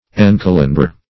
Search Result for " encalendar" : The Collaborative International Dictionary of English v.0.48: Encalendar \En*cal"en*dar\, v. t. To register in a calendar; to calendar.